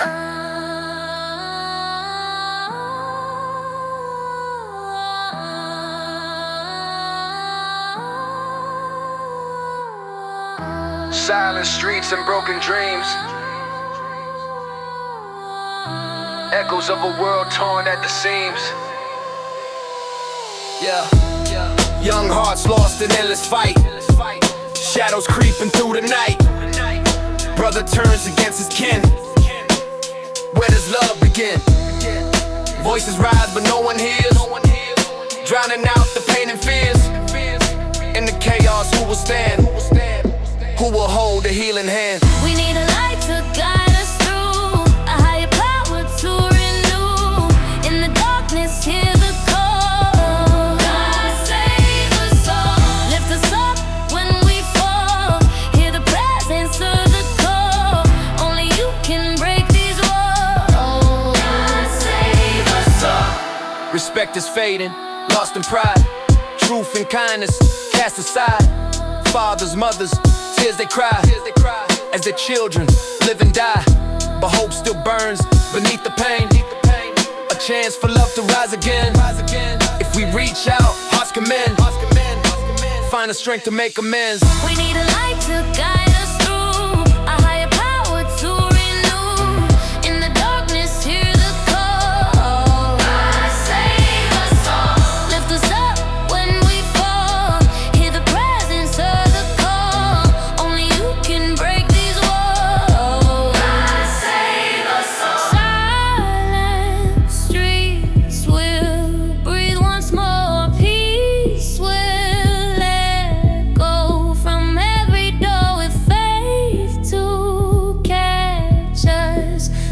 Genre: Gospel / Spiritual / Chant